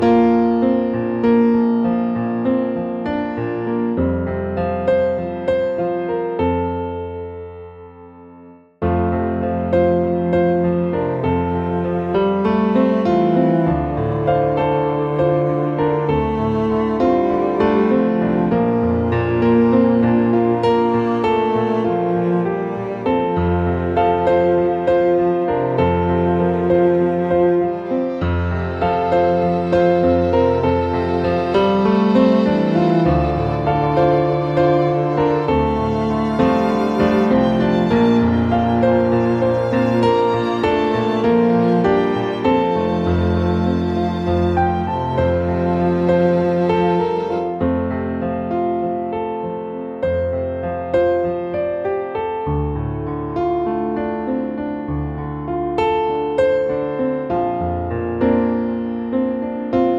Original Male Key